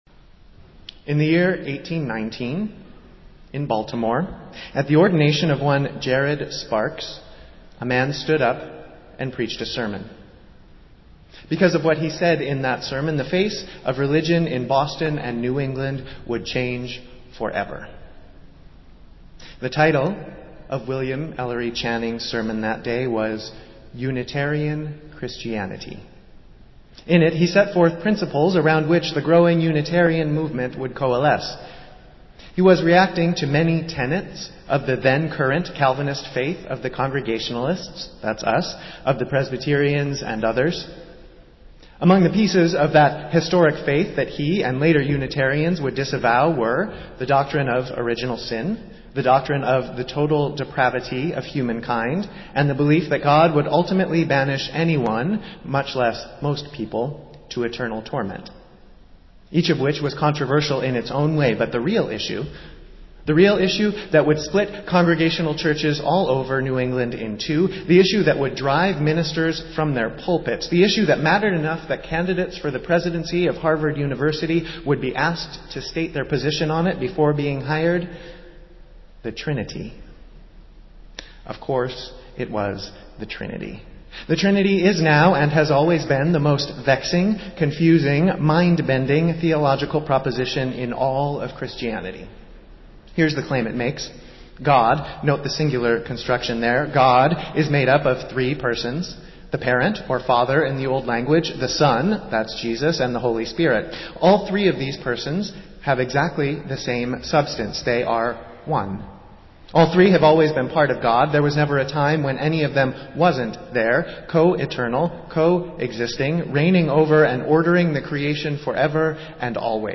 Festival Worship - Trinity Sunday